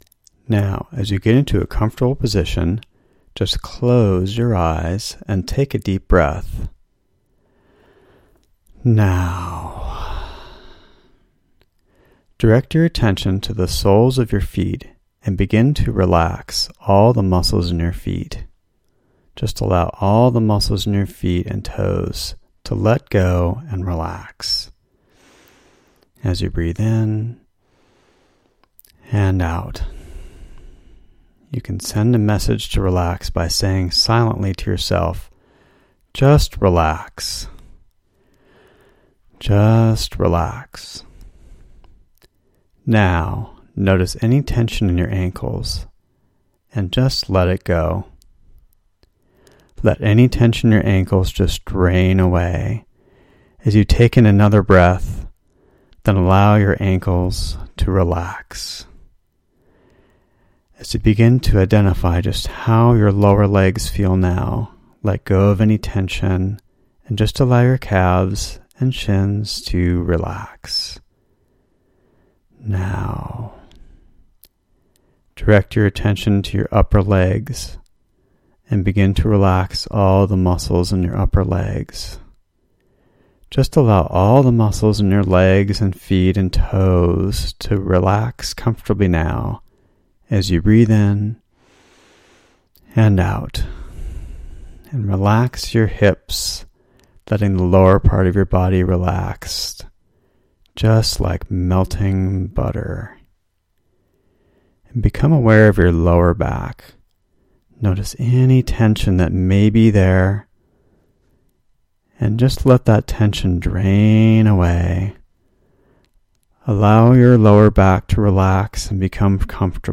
Bonus #1: Free companion exercise audio for Chapter 19
Progressive-Relaxation-Guided.mp3